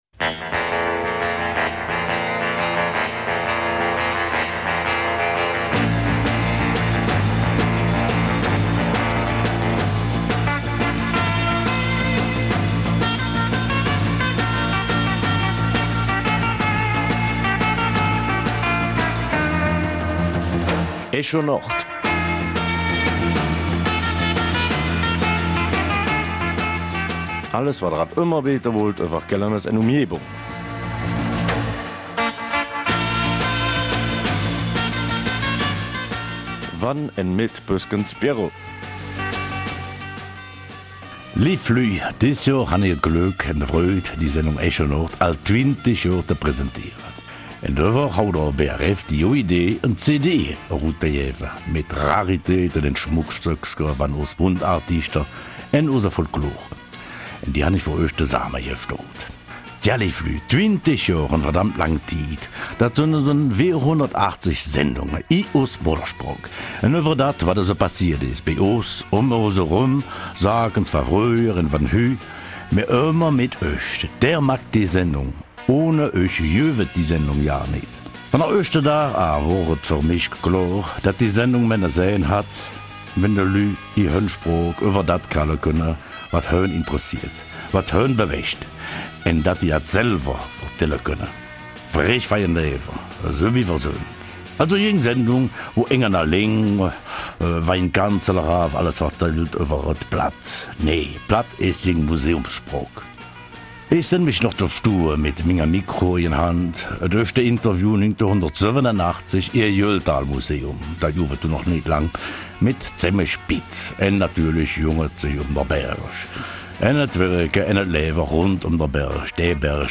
Kelmiser Mundart: 250. Sendung Echo Nord mit dem aktuellen Team
Die drei Moderatoren wagen einen Rückblick auf die letzten zehn Jahre und 250 Sendungen, die auch während der Pandemie lückenlos weitergeführt wurden.